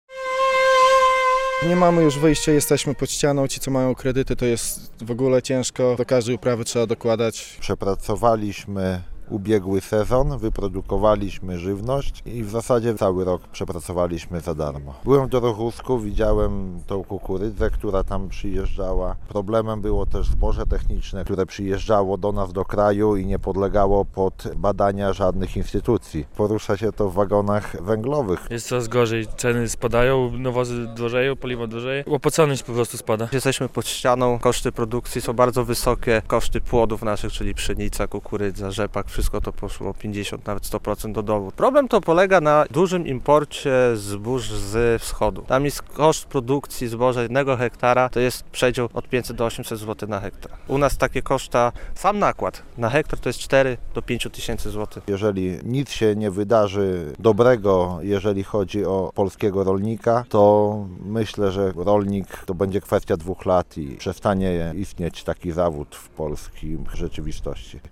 Protest rolników w Bielsku Podlaskim - relacja